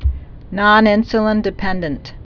(nŏn-ĭnsə-lĭn-dĭ-pĕndənt)